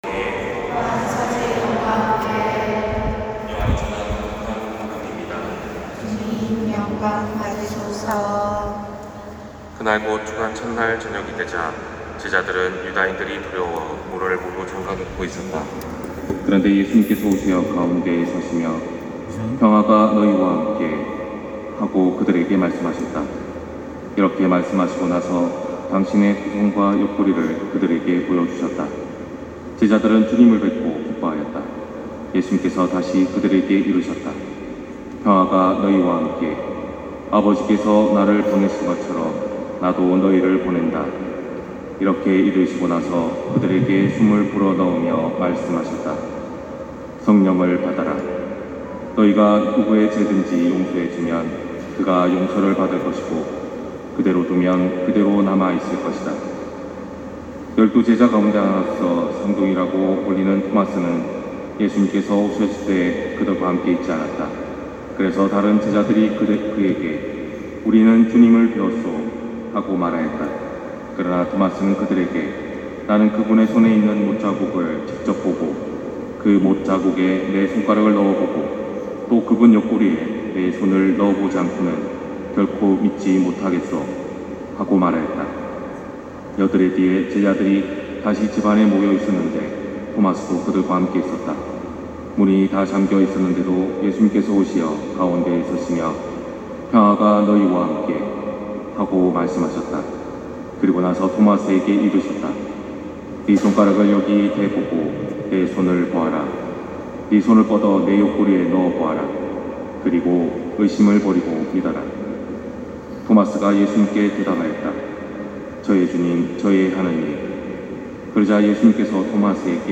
250426 신부님강론말씀